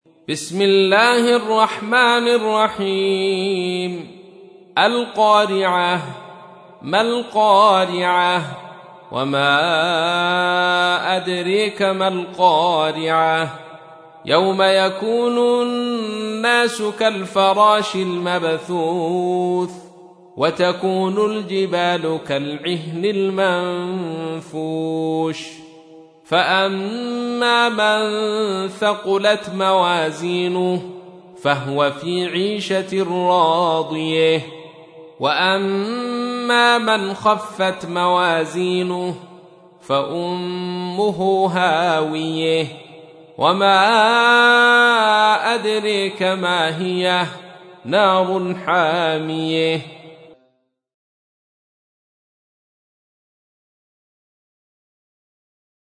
سورة القارعة / القارئ عبد الرشيد صوفي / القرآن الكريم / موقع يا حسين